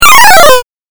8bitアクション | 無料 BGM・効果音のフリー音源素材 | Springin’ Sound Stock
レトロゲーム （42件）
8bit下降3.mp3